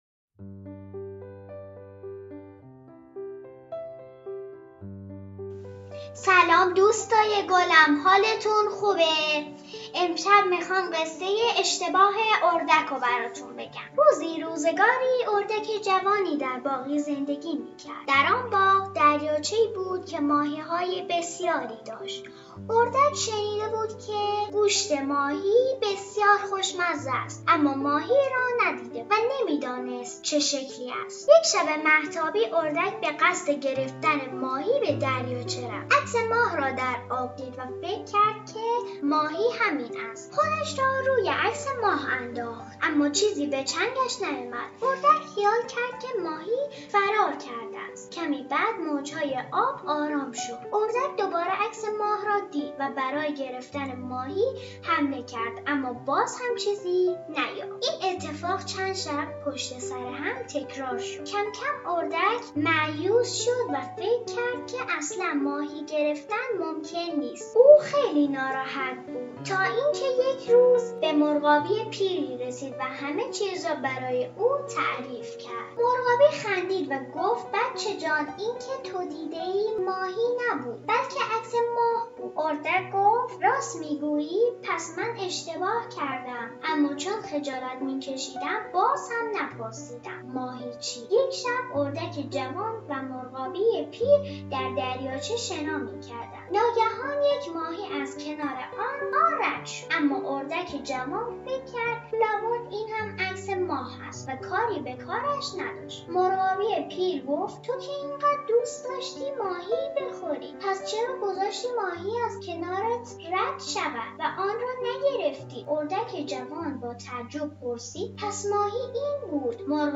قصه کودکان